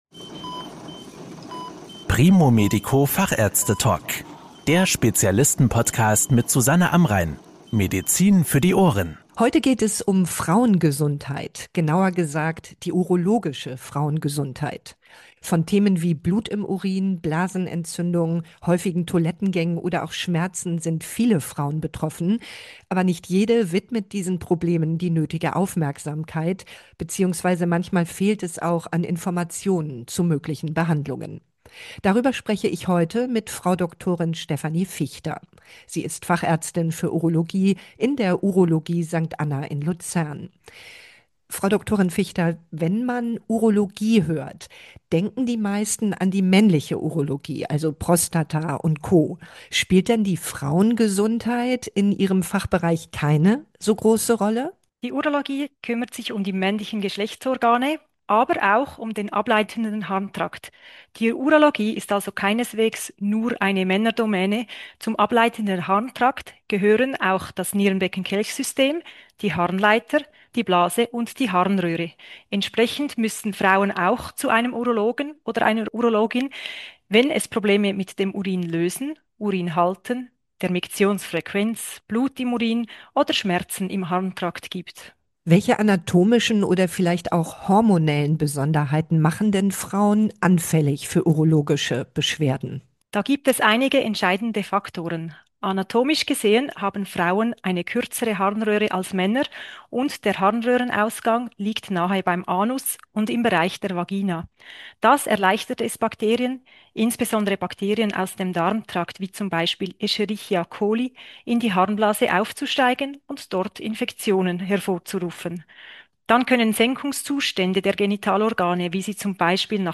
Fachärzte Talk